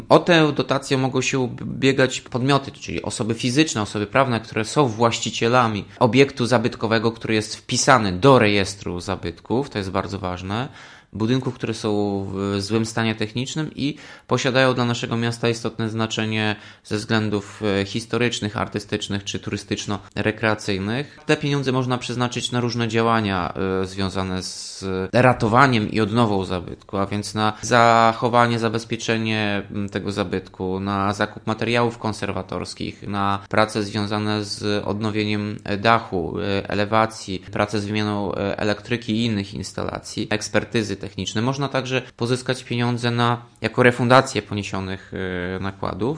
– Będzie to już ósma edycja konkursu. Dotychczas rozdysponowano milion 600 tysięcy zł- mówi Tomasz Andrukiewicz prezydent Ełku.